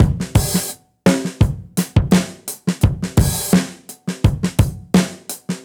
Index of /musicradar/dusty-funk-samples/Beats/85bpm
DF_BeatC_85-03.wav